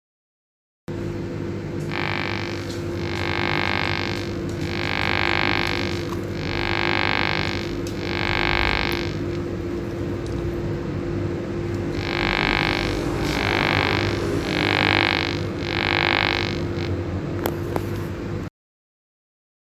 Blackstar HT20 Studio - Störgeräusche in unregelmässigen Abständen
Guten Abend liebes Musikerboard, hallo liebe Musikerfreunde, ich habe hier einen Blackstar HT-20 Studio Combo, der nach geraumer Zeit und immer wieder in unregelmäßigen Abständen eine "Symphonie" an Störgeräuschen von sich gibt.